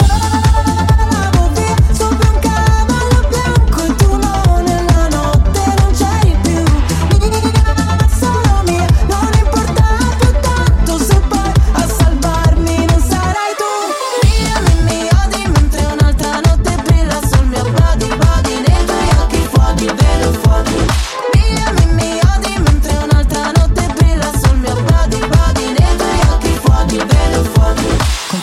Genere: pop,disco,trap,rap,dance,hit